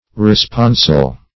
Search Result for " responsal" : The Collaborative International Dictionary of English v.0.48: Responsal \Re*spon"sal\, n. [Cf.LL. resposalis.] 1.